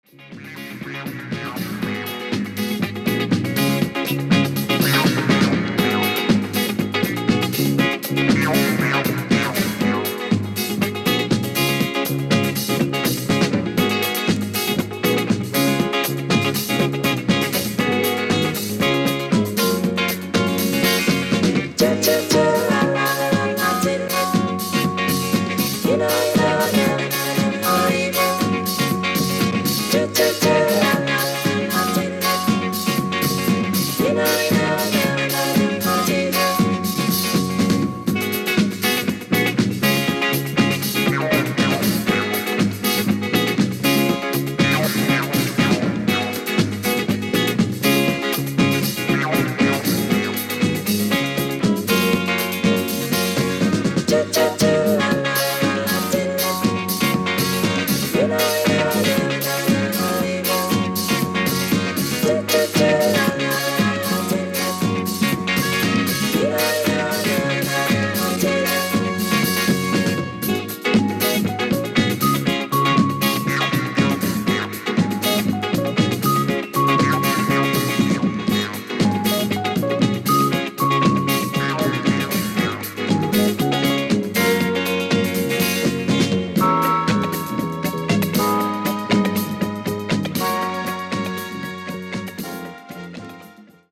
陽気な楽曲からダンスな楽曲をまとめた